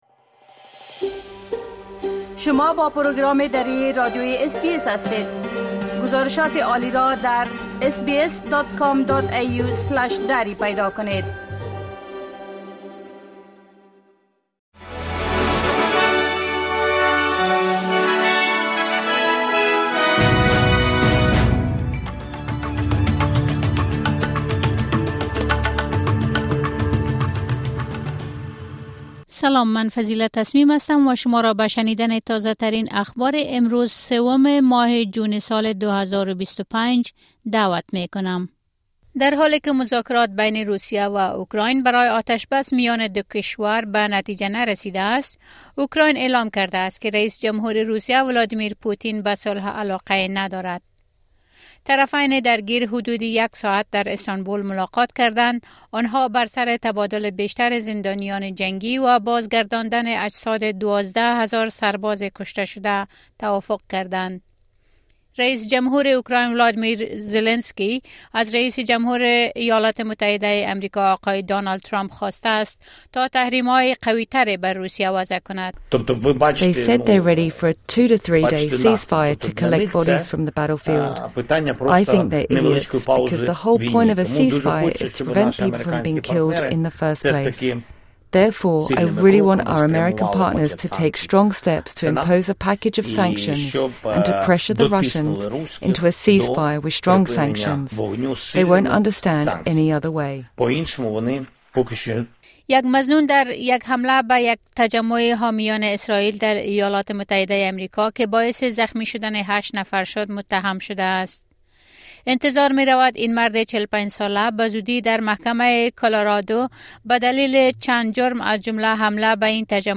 خلاصه مهمترين اخبار روز از بخش درى راديوى اس بى اس